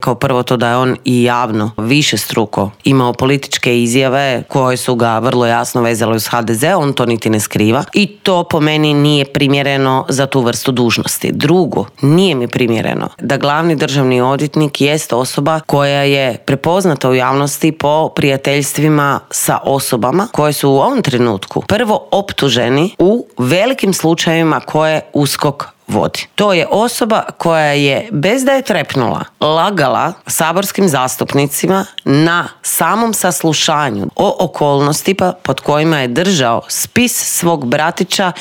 ZAGREB - U Intervjuu tjedna Media servisa gostovala je saborska zastupnica i premijerska kandidatkinja stranke Možemo Sandra Benčić, koja je prokomentirala nove izmjene koje je Vlada najavila uvrstiti u konačni prijedlog tzv. Lex AP-a, osvrnula se na reakciju vladajućih na jučerašnji prosvjed HND-a zbog kaznenog djela o curenju informacija, ali i na kandidata za glavnog državnog odvjetnika Ivana Turudića.